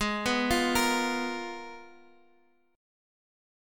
G#m6add9 Chord
Listen to G#m6add9 strummed